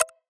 check-on.wav